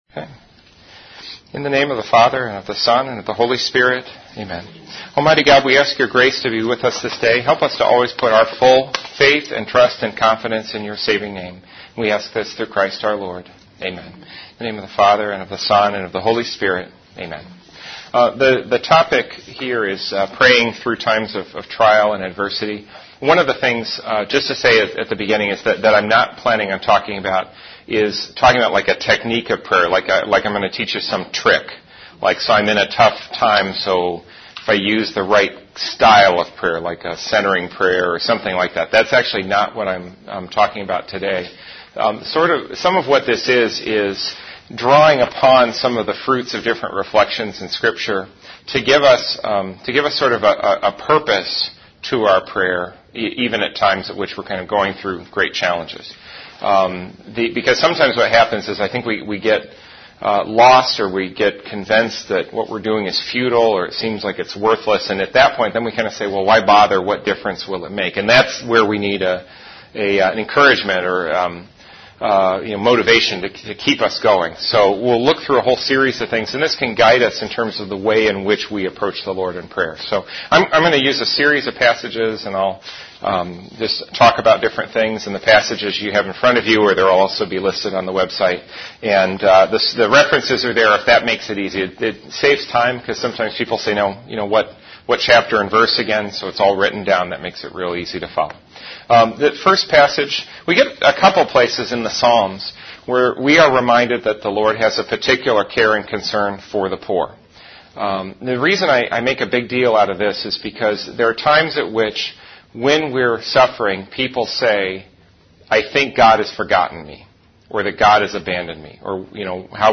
Return to Scripture Presentations Homepage The following talk was prepared as part of a workshop offered in April, 2009, to help people deal with times of economic or financial distress. This talk reflects on a number of scripture passages to help a person who is coping with a crisis situation.